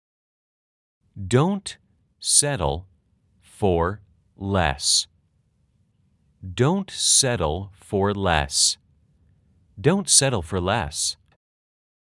아주 천천히-천천히-빠르게 3번 반복됩니다.
/ 도온 쎄를 퍼 / 레에스 /
settle /쎄틀/은 /쎄를/로 발음해주세요.